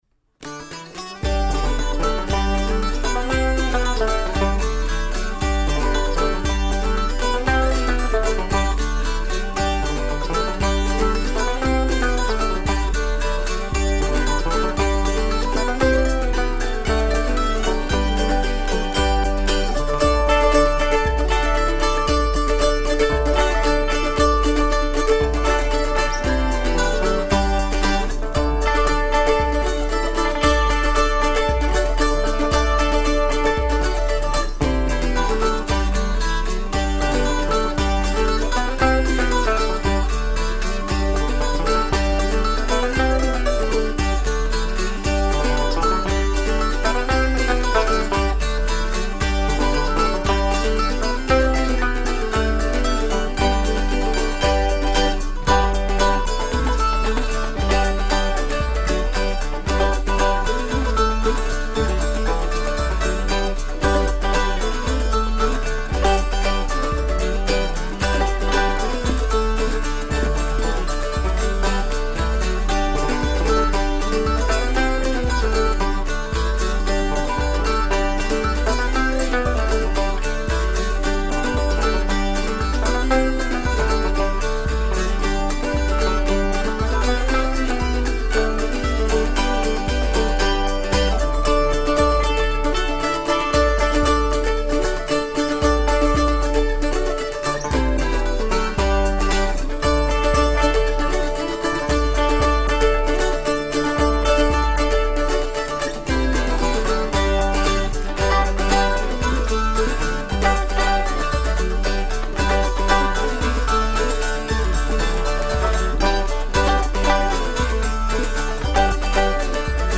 I wrote this tune for dulcimer
Folk
World music